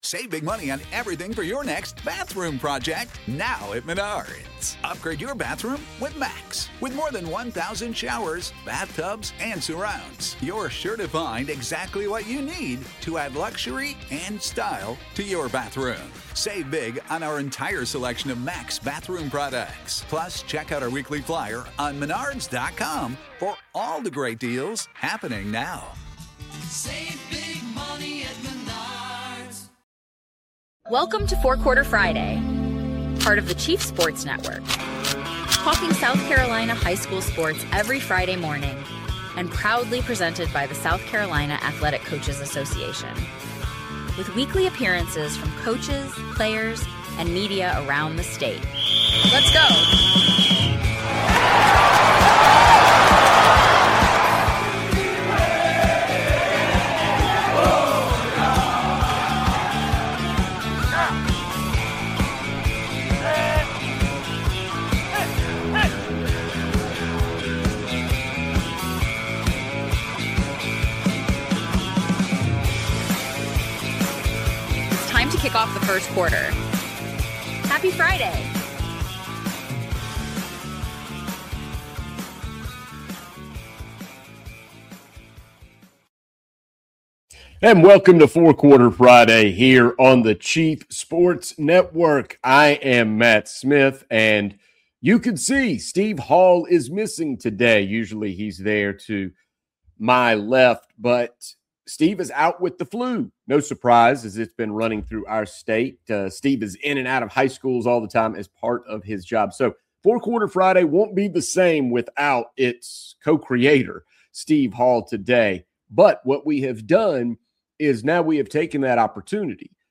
hosts solo. Topics include the basketball playoffs, the coaches clinic and a South Carolina High School Baseball tradition unlike any other on the coast in Georgetown.